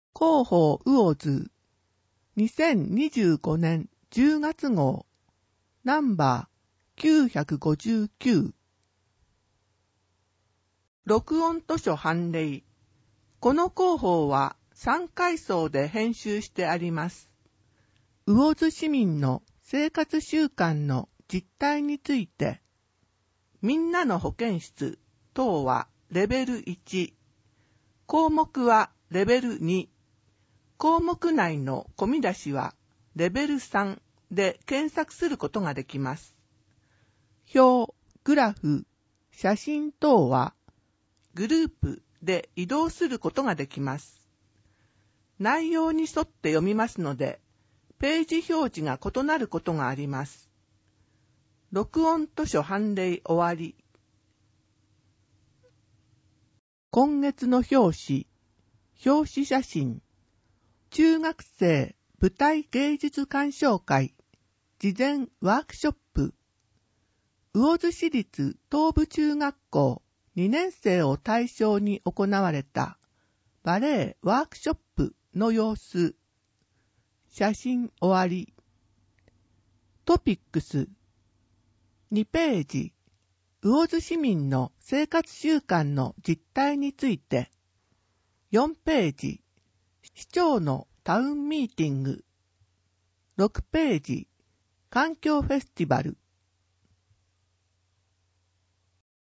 声の広報
魚津市では、音訳サークルうぐいすの会にご協力いただき、視覚障害の方を対象に「広報うおづ」の音訳CDを無料で発送しています。